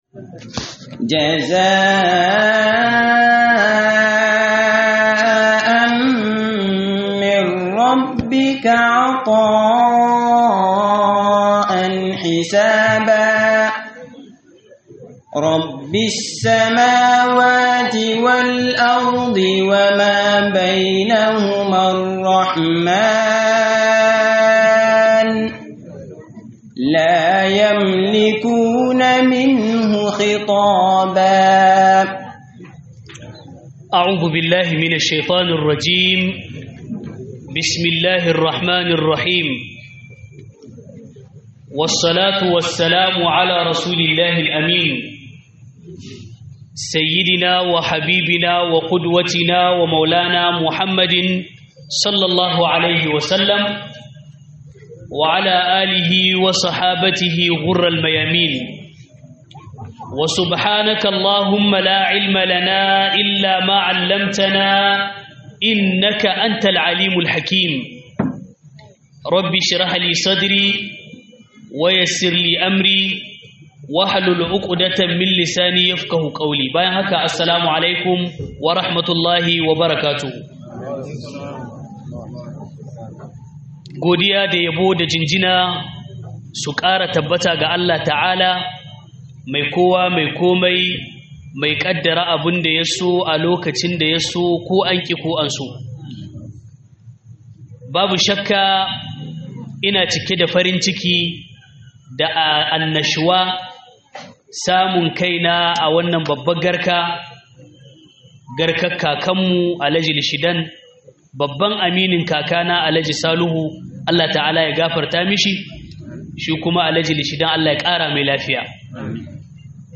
Muhadara Akna Aure da geran sa